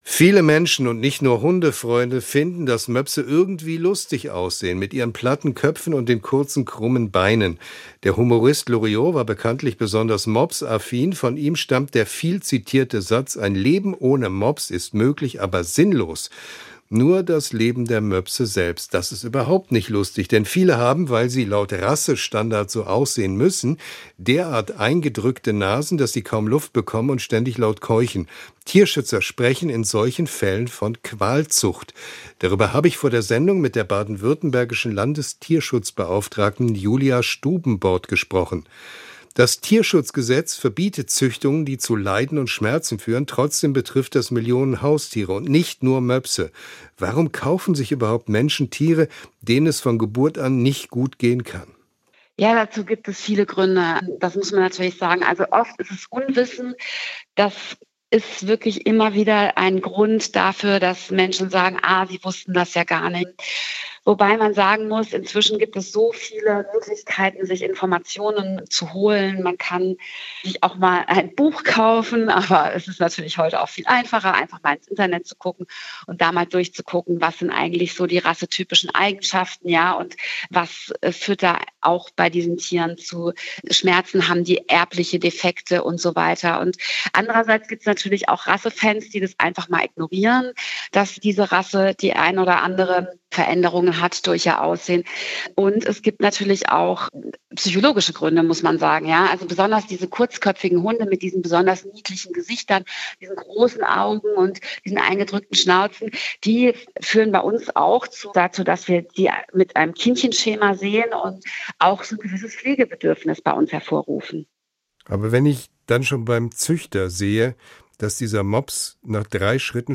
Nur, wenn auch die Zuchtverbände ihre Rassenstandards anpassen, sagt die baden-württembergische Landestierschutzbeauftragte Julia Stubenbord